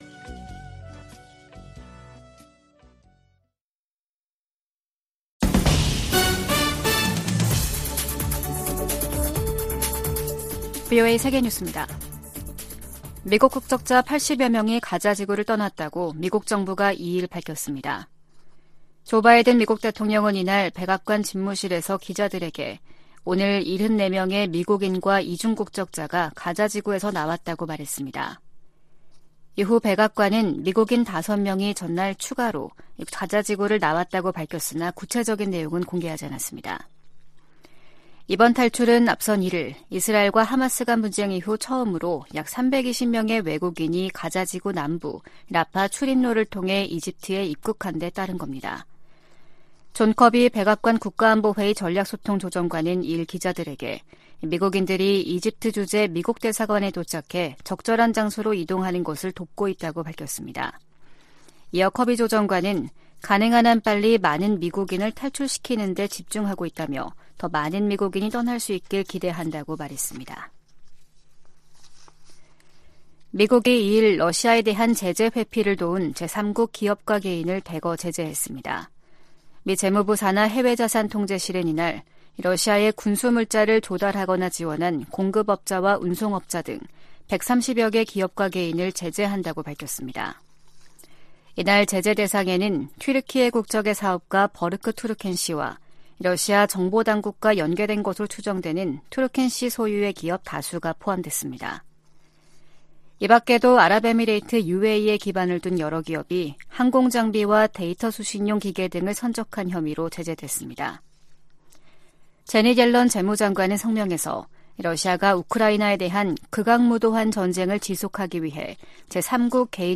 VOA 한국어 아침 뉴스 프로그램 '워싱턴 뉴스 광장' 2023년 11월 3일 방송입니다. 미 국방정보국장이 북한-이란-러시아 연계를 정보 당국의 주시 대상으로 지목했습니다. 조 바이든 미국 대통령이 공석이던 국무부 부장관에 커트 캠벨 백악관 국가안보회의 인도태평양 조정관을 공식 지명했습니다. 북한의 최근 잇따른 대사관 폐쇄는 국제사회의 제재가 작동하고 있다는 증거라고 미국 전직 외교관들이 분석했습니다.